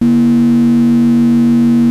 BUCHLA A#4.wav